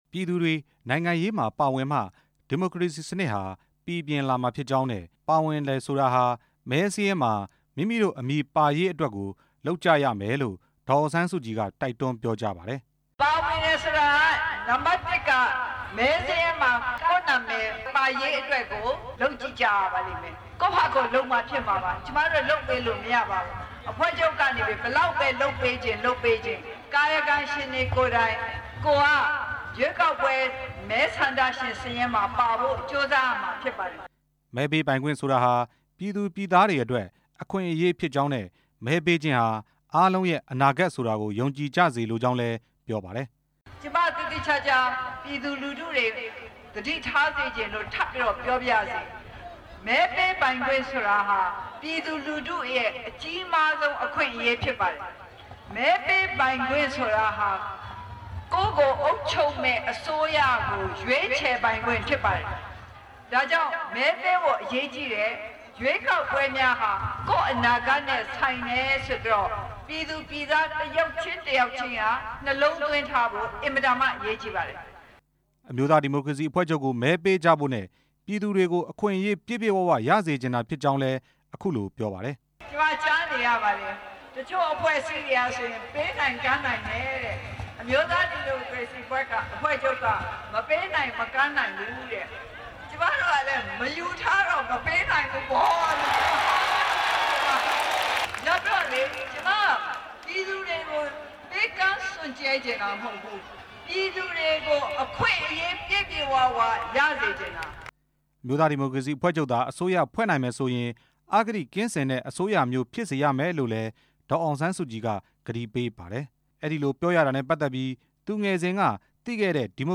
ဒီကနေ့ မန္တလေးတိုင်းဒေသကြီး ရမည်းသင်းမြို့က ကျောက်ဖြူကုန်းကွင်းပြင်မှာပြုလုပ်တဲ့ ရွေးကောက်ပွဲအသိ ပညာပေးဟောပြောပွဲမှာ ဒေါ်အောင်ဆန်းစုကြည် က ကတိပေးပြောလိုက်တာဖြစ်ပါတယ်။
ဒီကနေ့ ရမည်းသင်းမြို့က ဟောပြောပွဲကို ဒေသခံပရိသတ် ၃ဝဝဝ ကျော်လောက် တက်ရောက်တယ်လို့ ခန့်မှန်းကြ ပါတယ်။